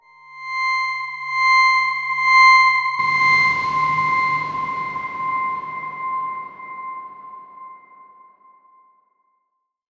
X_Darkswarm-C6-mf.wav